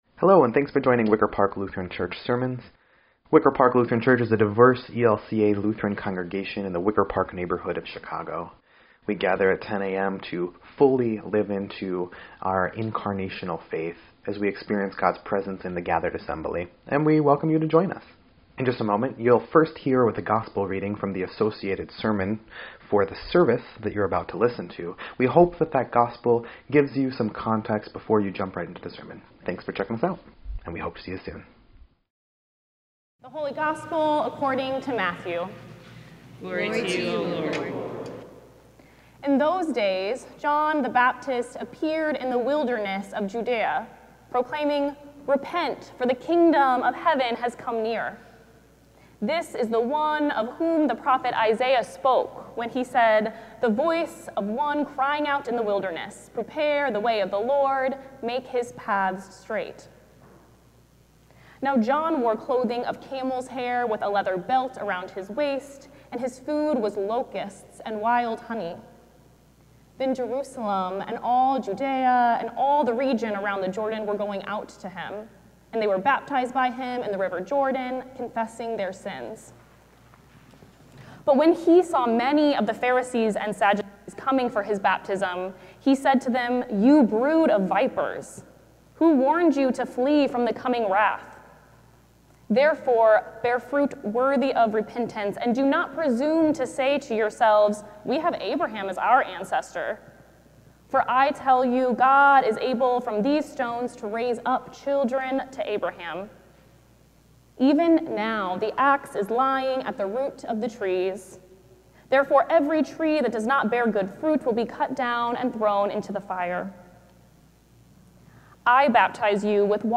12.7.25-Sermon_EDIT.mp3